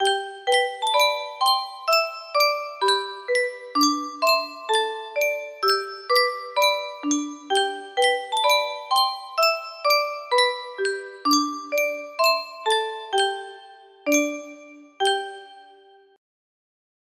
Clone of Yunsheng Music Box - Wheels on the Bus 1319 music box melody